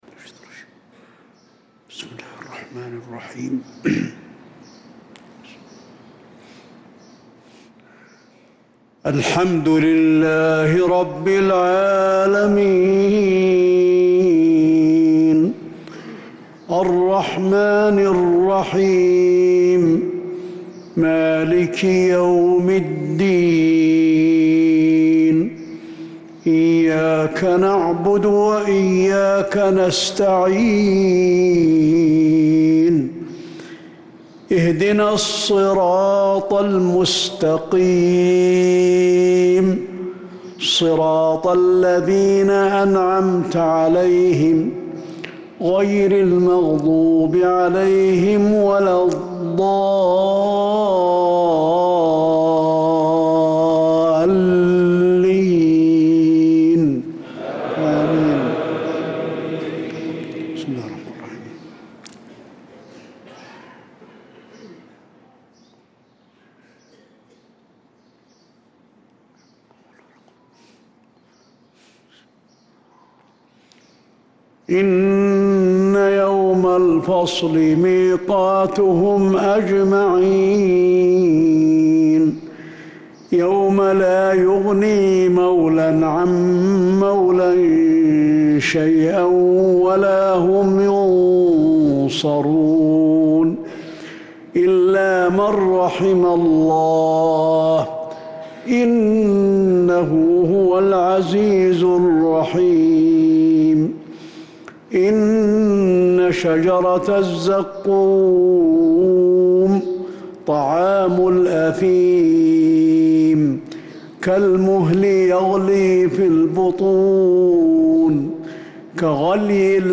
صلاة المغرب للقارئ علي الحذيفي 2 ذو القعدة 1445 هـ
تِلَاوَات الْحَرَمَيْن .